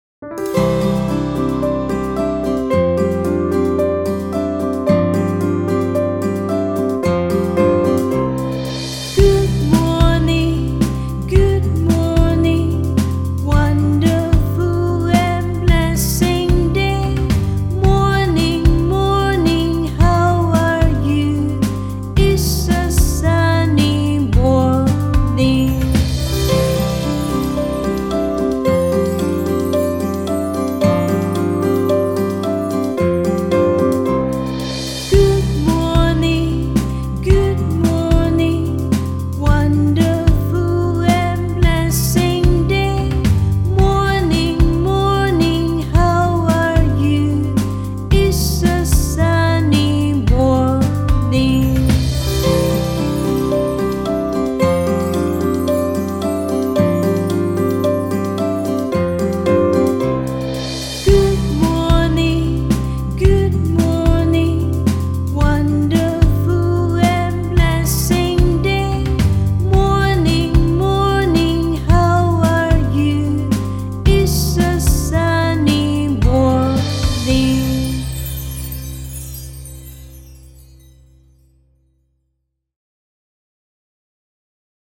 Singing
Slow